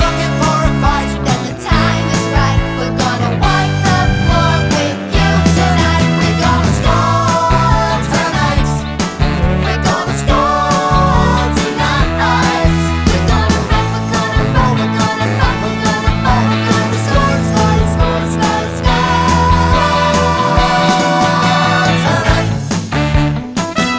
Soundtracks